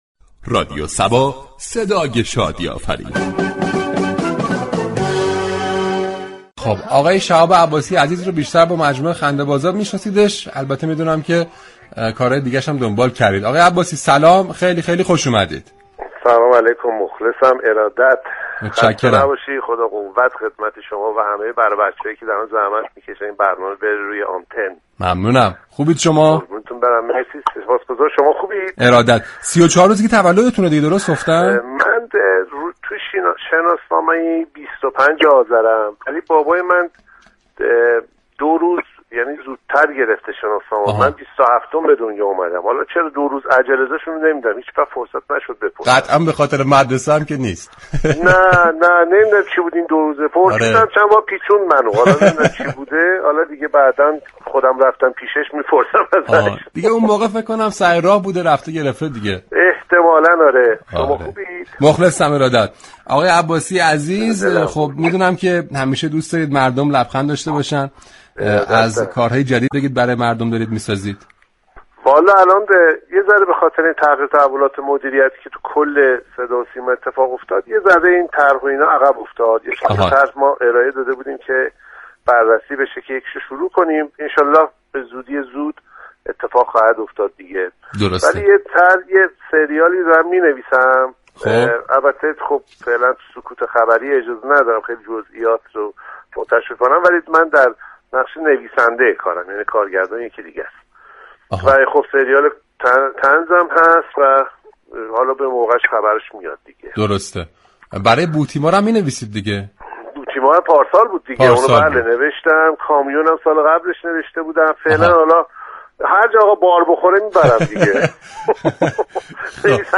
شهاب عباسی بازیگر و كارگردان طناز در گفتگو با «رادیو صبا» از علاقه اش به كارگردانی گفت